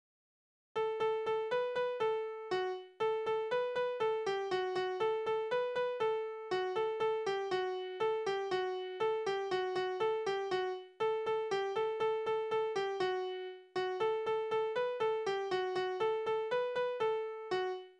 Tonart: D-Dur
Taktart: 2/4
Tonumfang: Quarte
Besetzung: vokal
Anmerkung: gesprochene Passagen